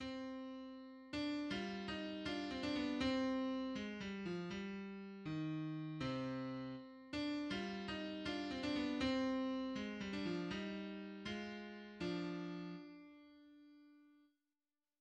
The cascading string figures are changed, and the overall mood is much more somber than in previous versions.